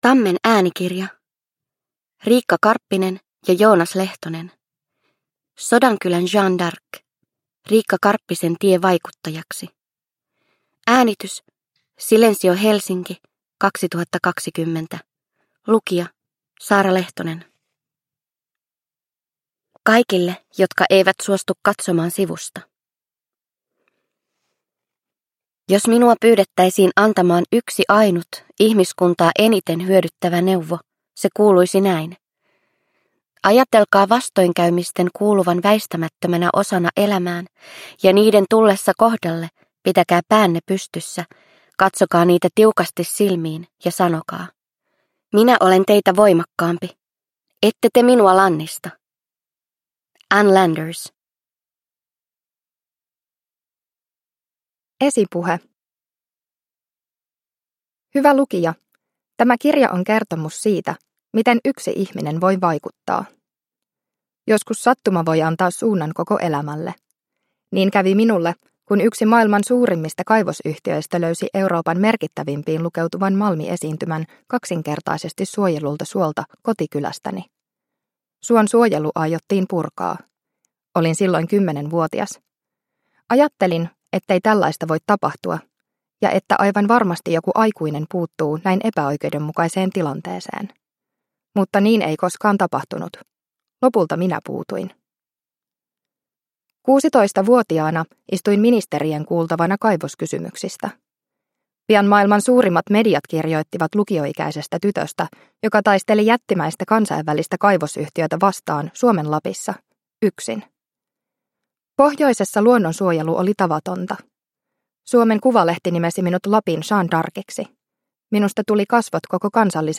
Sodankylän Jeanne d'Arc – Ljudbok – Laddas ner